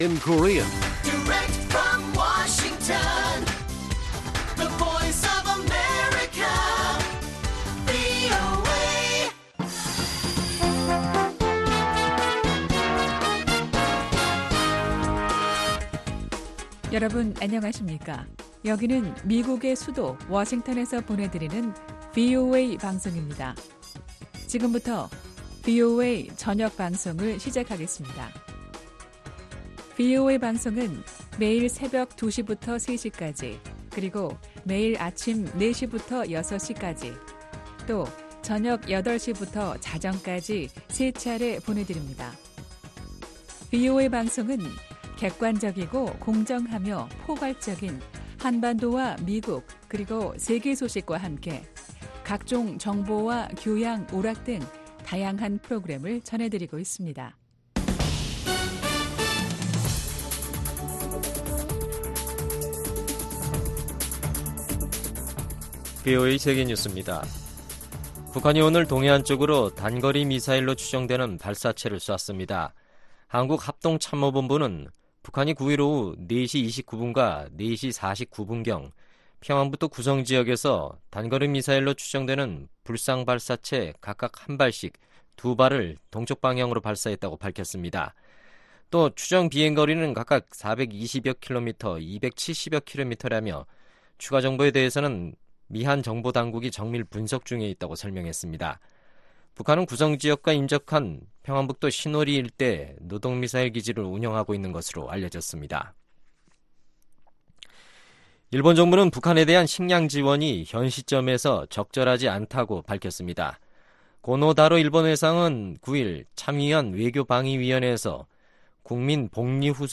VOA 한국어 간판 뉴스 프로그램 '뉴스 투데이', 2019년 5월 9일 1부 방송입니다. 북한이 오늘 단거리 미사일로 추정되는 발사체 2발을 발사했습니다. 마이크 폼페오 국무장관은 전 세계가 동참하는 대북 압박 캠페인이 계속돼야 한다고 밝혔습니다.